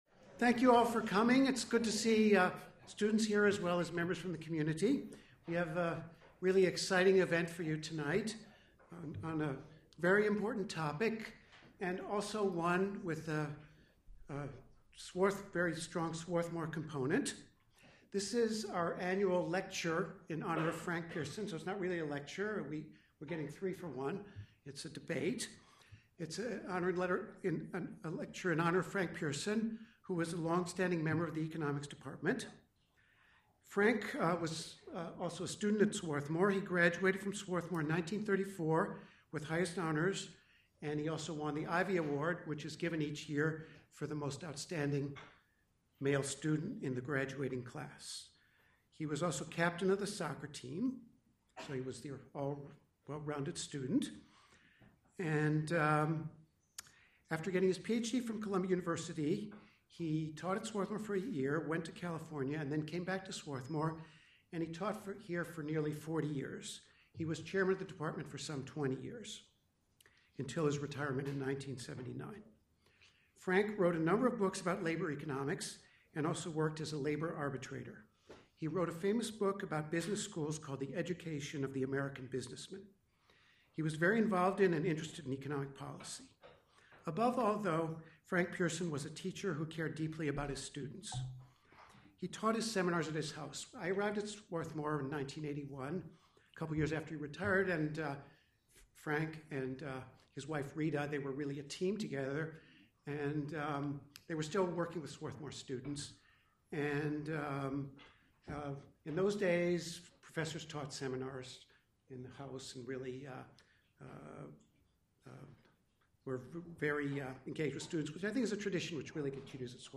Department of Economics Frank C. Pierson Lecture